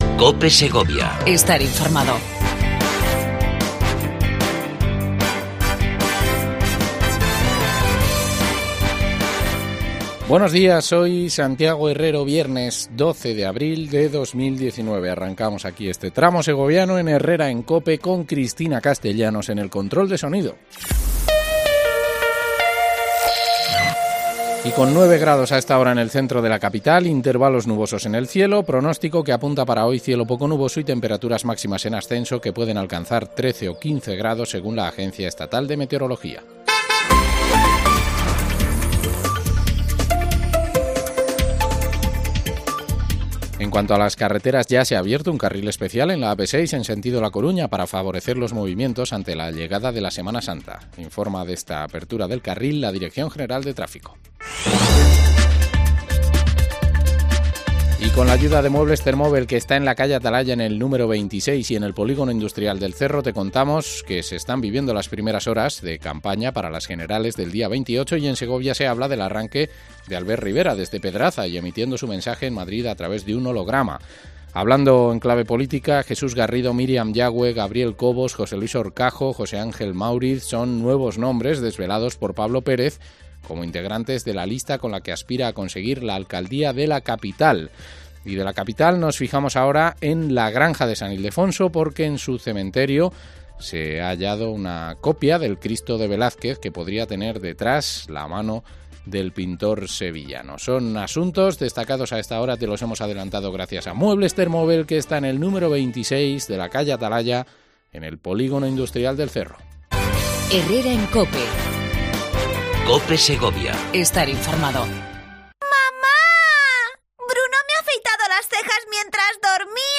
AUDIO: Entrevista a Sara Dueñas, Concejala de Cultura y Juventud de la Diputación Provincial. Se estrena la Aplicación Móvil del bibliobús ¡Descubre...